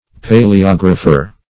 Paleographer \Pa`le*og"ra*pher\, n. One skilled in paleography; a paleographist.